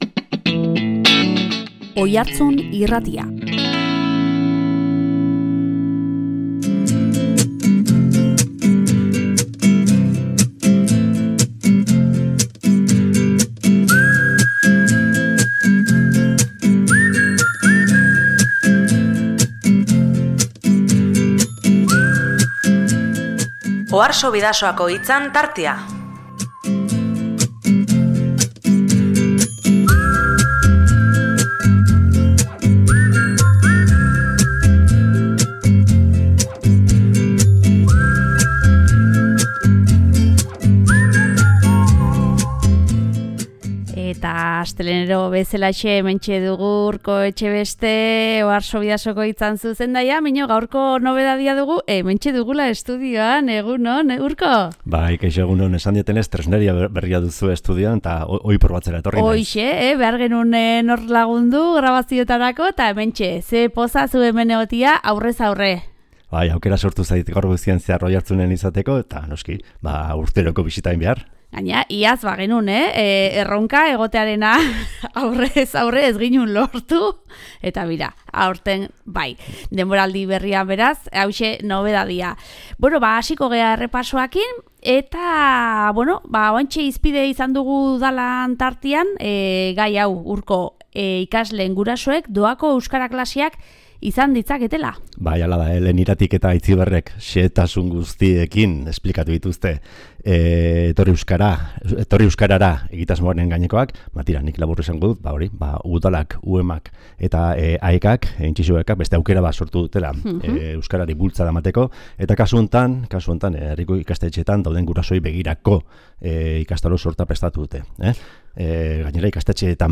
Oiartzun Irratiko estudioan, eskualdeko albisteen errepasoa egiteko.